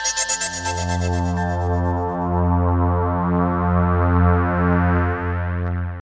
Index of /90_sSampleCDs/Zero-G - Total Drum Bass/Instruments - 1/track14 (Pads)